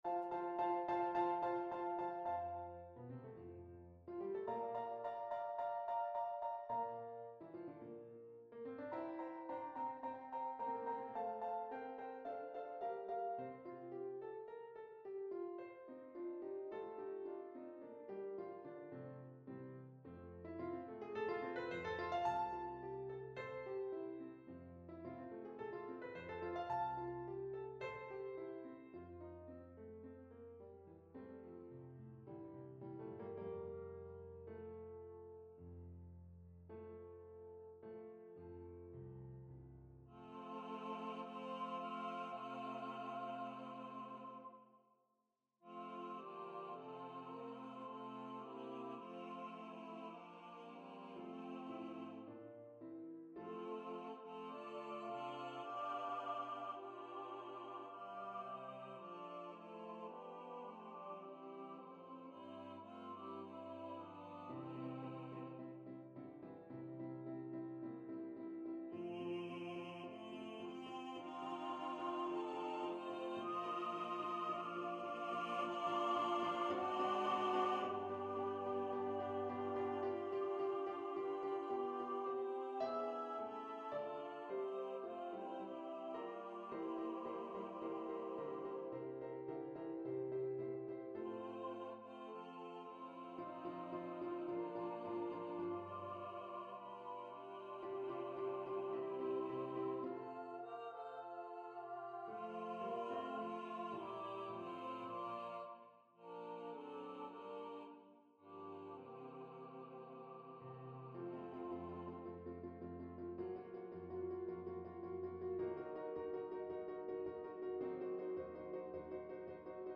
SSATB solo quintet or mixed chorus
Keyboard accompaniment
Note Performer 4 mp3 Download/Play Audio